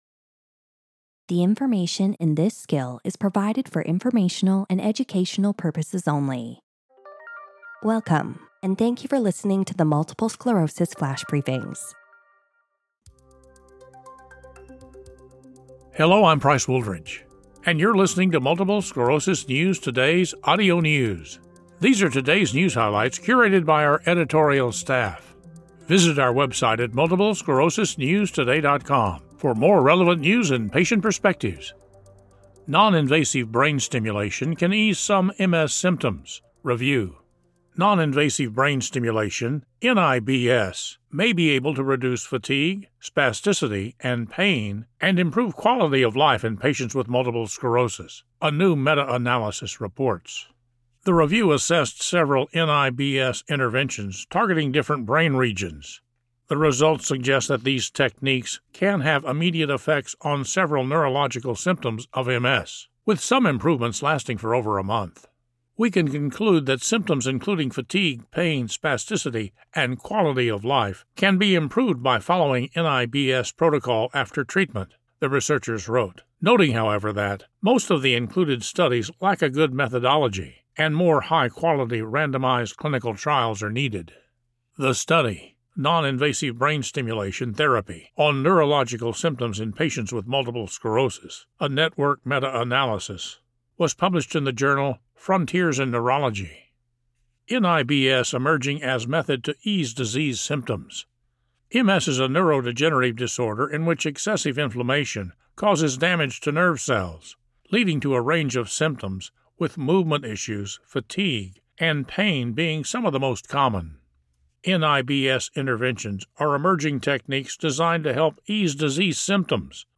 reads about a study on noninvasive brain stimulation that was able to improve fatigue, spasticity, pain, and quality of life in patients with MS.